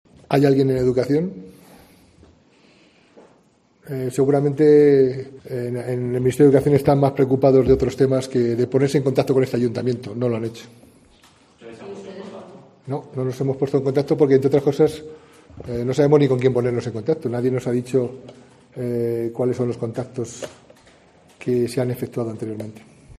José Mazarías, alcalde de Segovia, sobre el proyecto del CITAR en el edificio de Emprendedores